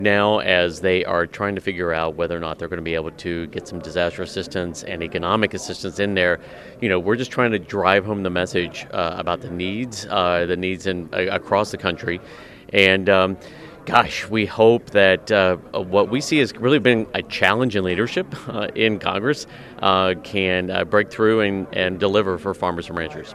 Genre: Blues.